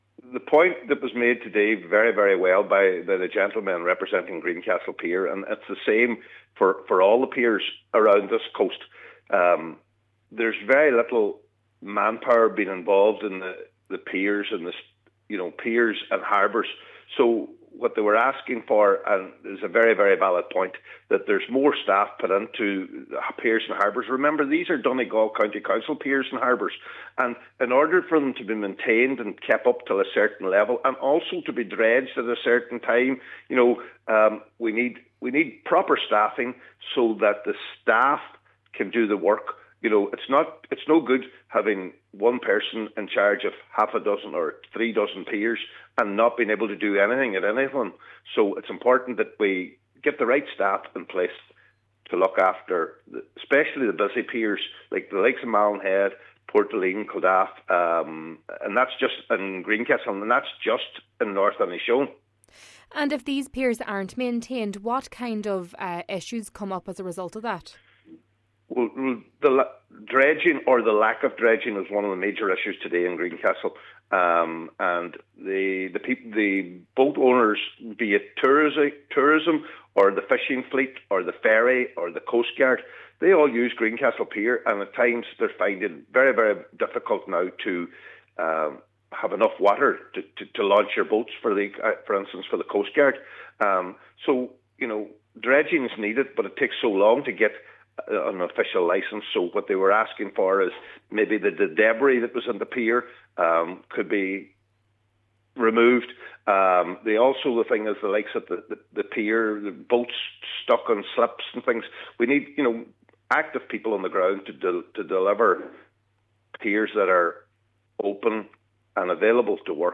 Cllr Ali Farren is asking that in the meantime, debris should be removed to ensure safe and efficient access for all: